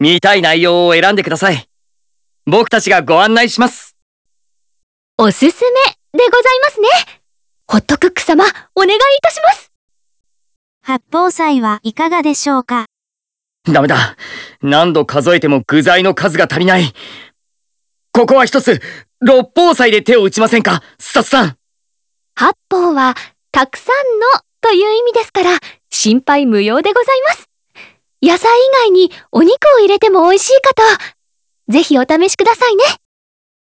続いてホットクックのボイスも聞いていただきます。これは実際に作れる料理を紹介するボイスです。